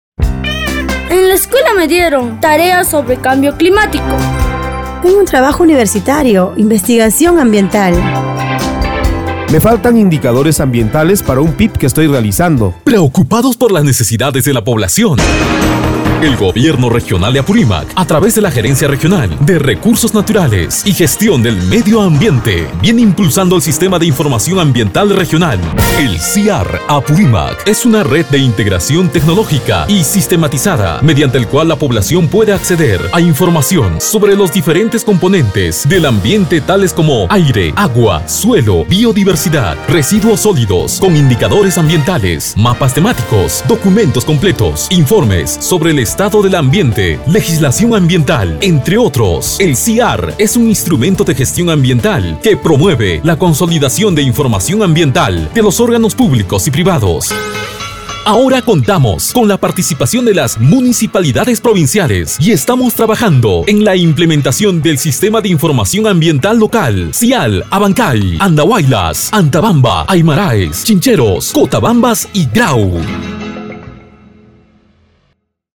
Spot Radial del Proyecto SIAR y SIALs Apurimac | SINIA